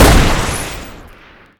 shoot1.ogg